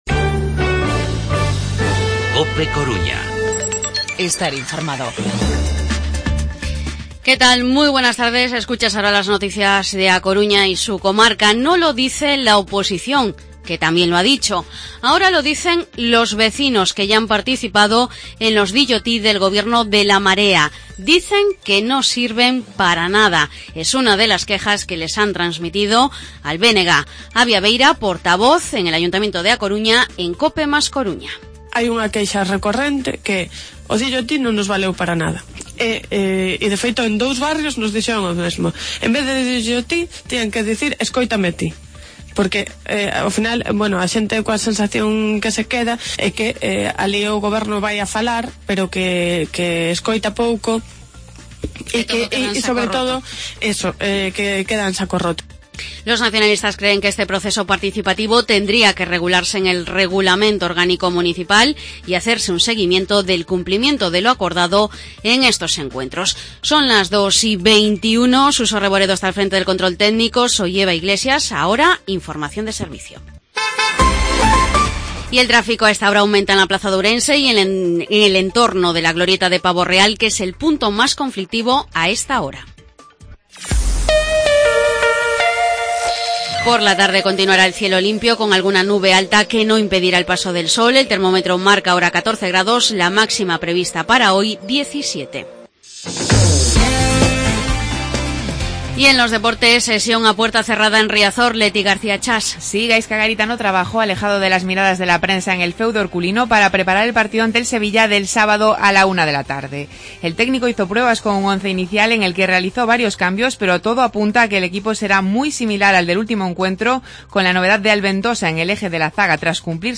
Informativo mediodía 16 de noviembre 2016